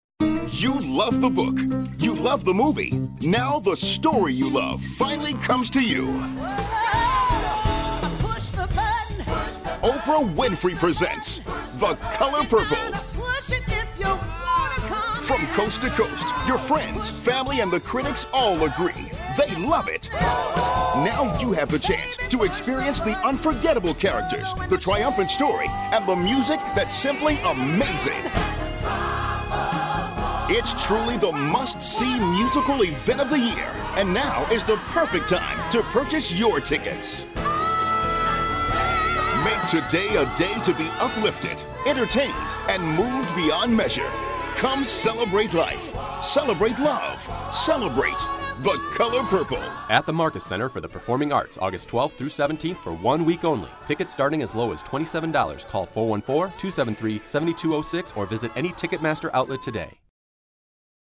The Color Purple Radio Commercial